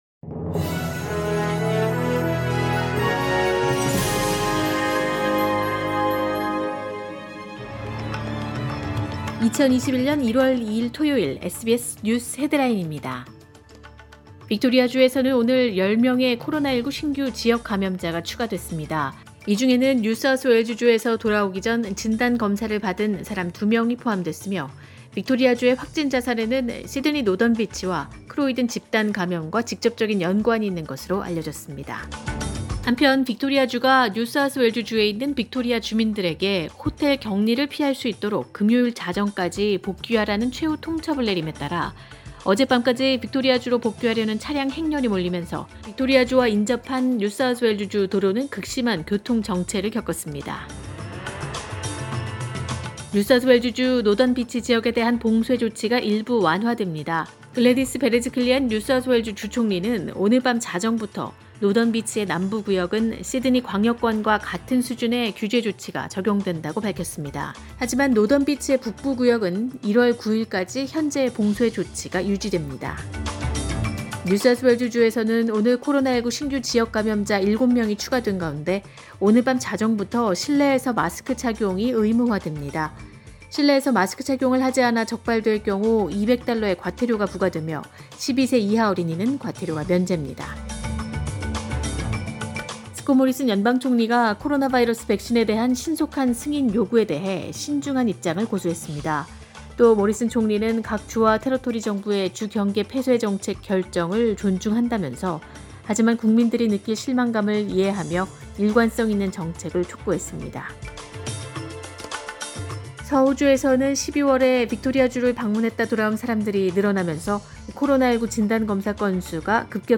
2021년 1월 2일 토요일 오전의 SBS 뉴스 헤드라인입니다.